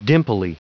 Prononciation du mot dimply en anglais (fichier audio)
Prononciation du mot : dimply